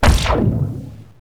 space-gun.wav